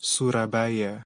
^ (English: /ˌsʊrəˈbə/ SUU-rə-BY; Indonesian: [suraˈbaja]
Id-Surabaya.ogg.mp3